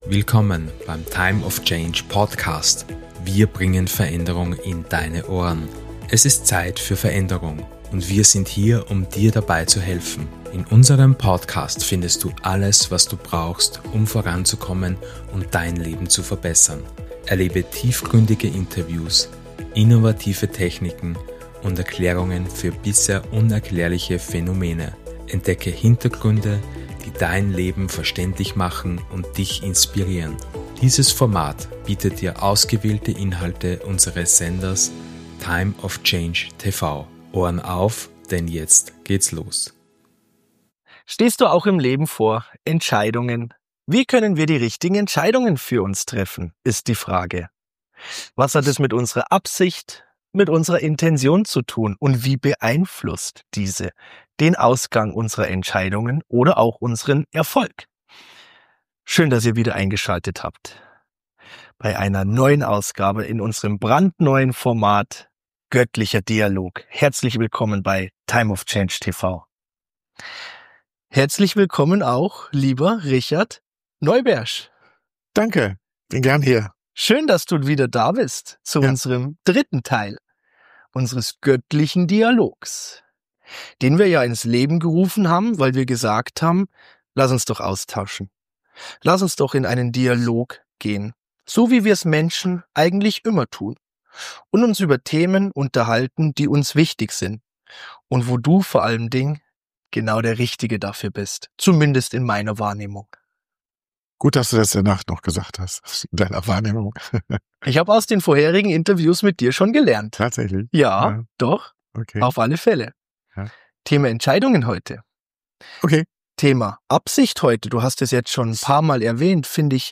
Lass Dich von diesem göttlichen Dialog inspirieren und entdecke neue Perspektiven für Dein eigenes Leben und Wohlbefinden.